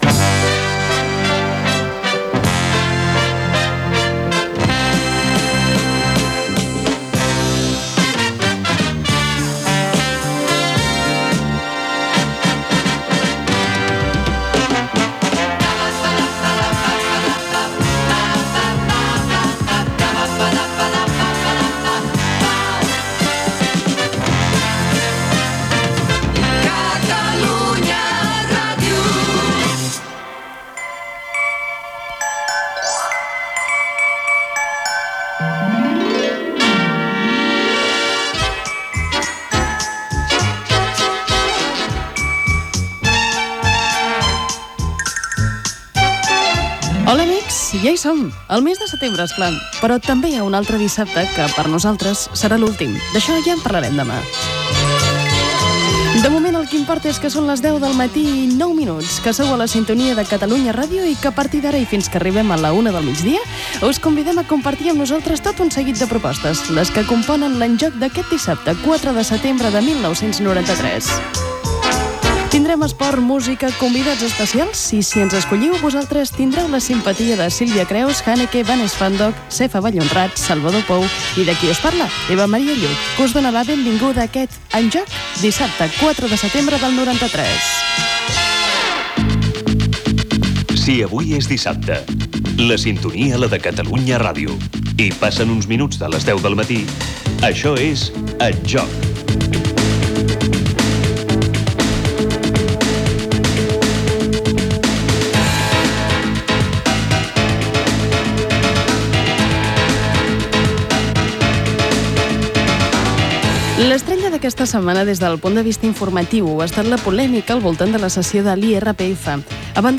Indicatiu de la emissora i sintonia i presentació del programa matinal d'entreteniment i informació. Indicatiu del programa, informació i acaba amb un concurs telefònic.
Info-entreteniment